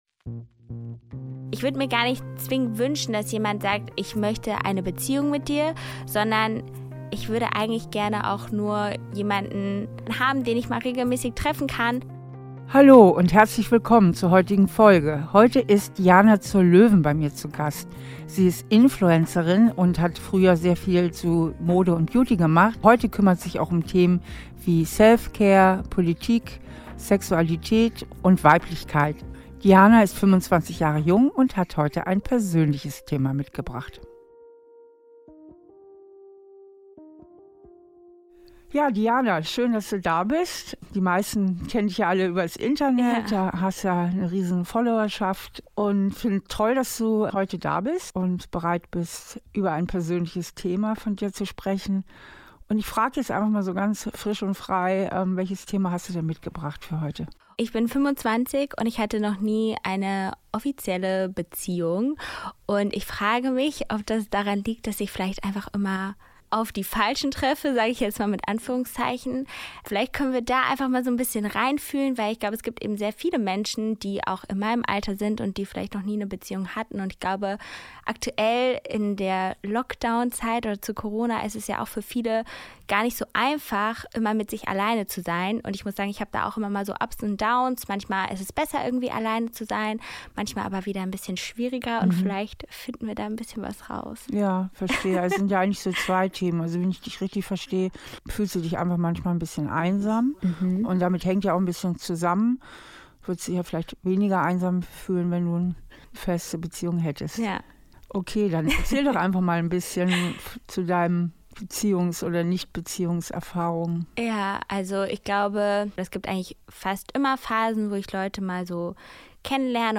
Im Gespräch mit Stefanie Stahl findet sie es heraus.